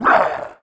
Sound / sound / monster / greenfrog_general / attack_1.wav
attack_1.wav